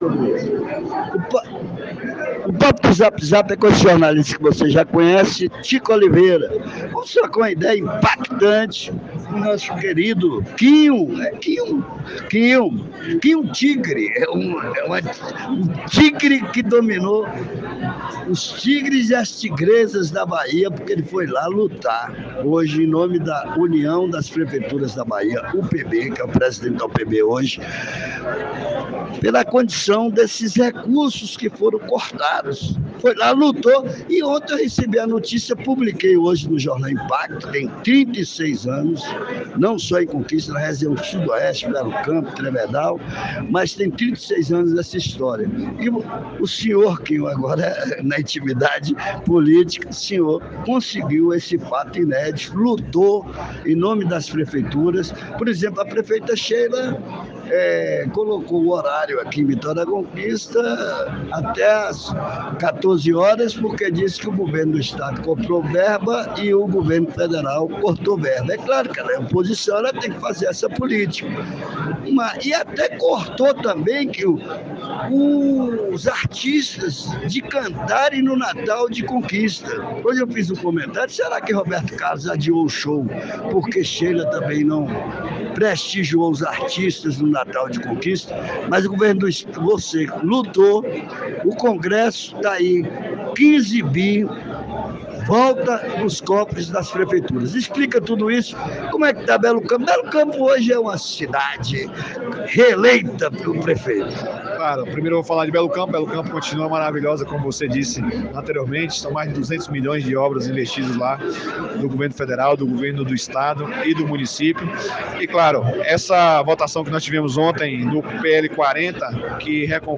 No momento da entrevista no encontro do governador com empresários em Vitória da Conquista .